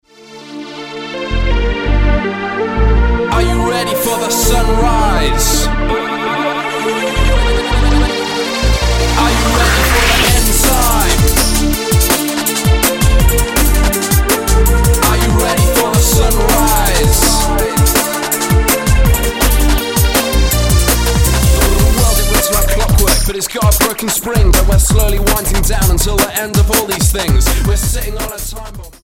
A band of dance music evangalists from York
Style: Dance/Electronic